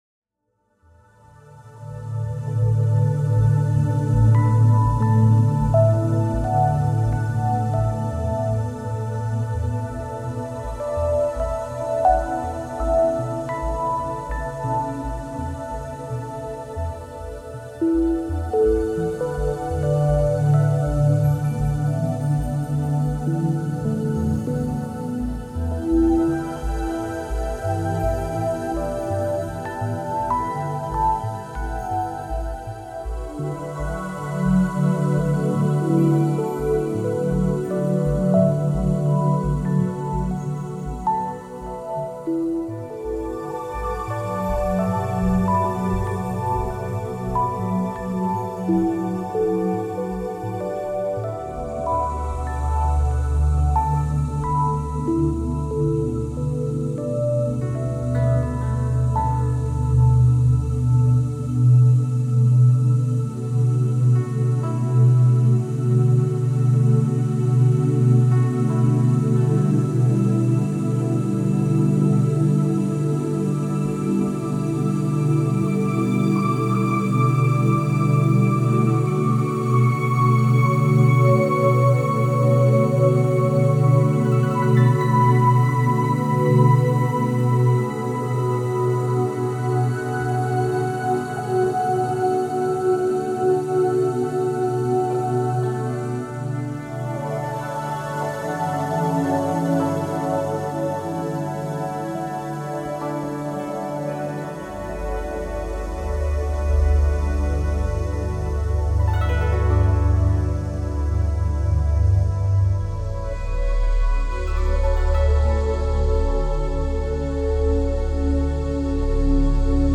banda sonora melodía sintonía